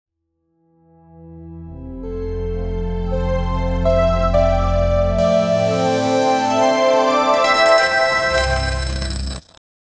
Cinematic Soundscape
cinematic-soundscape-10-s-ivr5inmb.wav